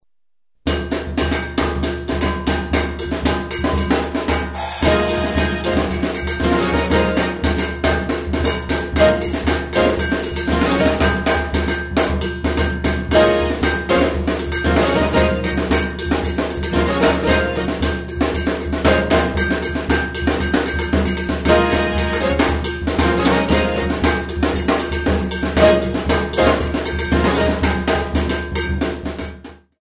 4/4  mm=116